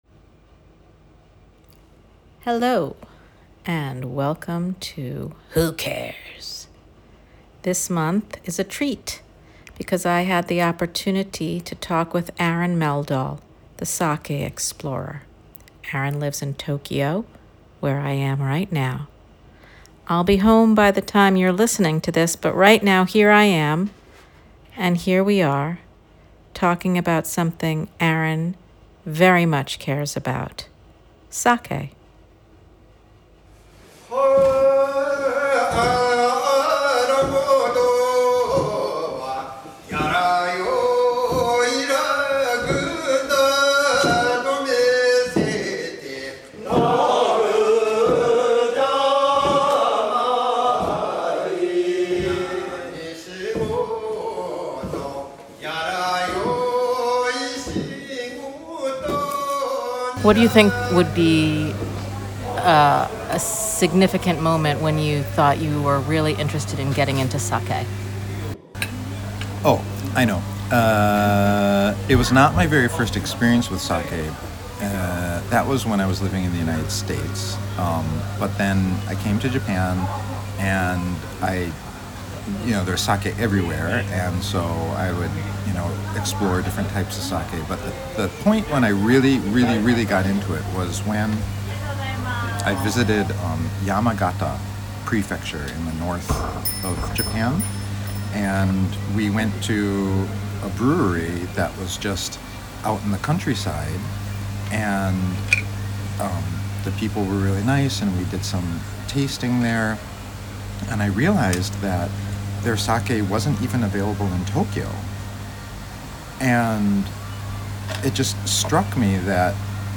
His goal is to attract more fellow explorers and help make sake an accessible beverage option and ensure its vitality for centuries to come. In addition to our interview you can hear Nanbu Ryuu Ara Motosuri-uta, a song sung when grinding steamed rice kogi, and water into the yeast starter mash (moto).